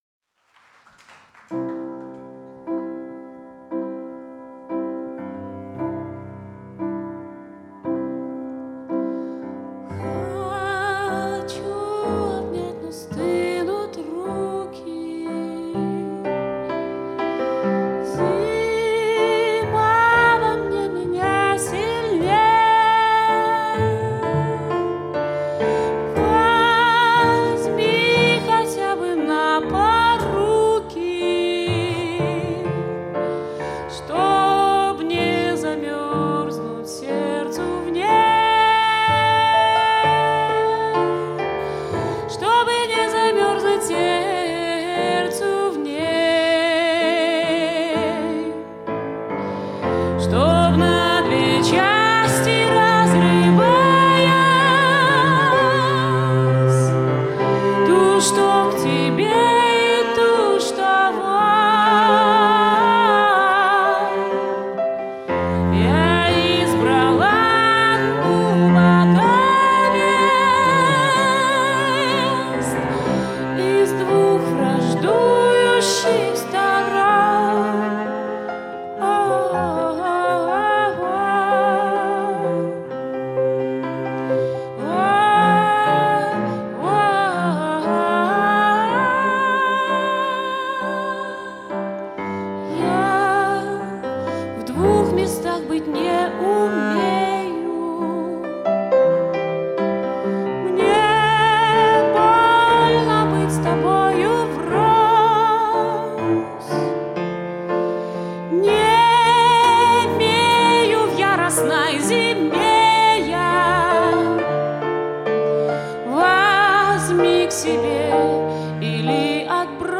Акустический альбом.
голос, гитара
виолончель
рояль, клавиши
перкуссия